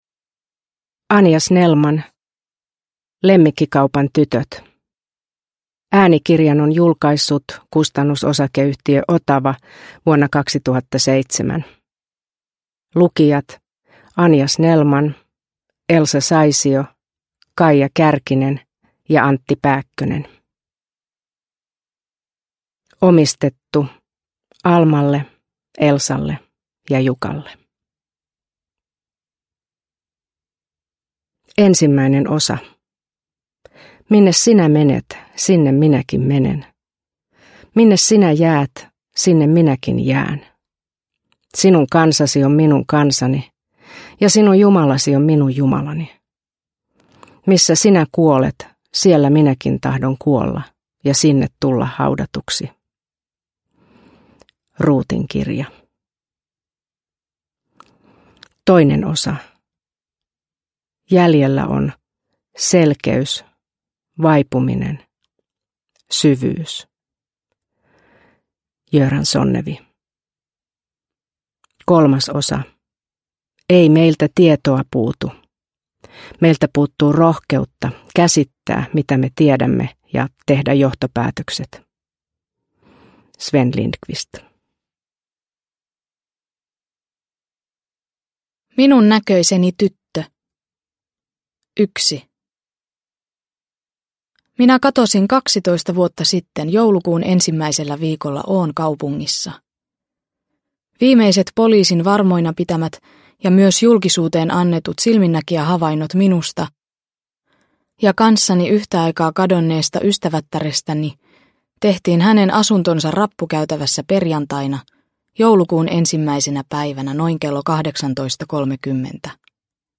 Lemmikkikaupan tytöt – Ljudbok – Laddas ner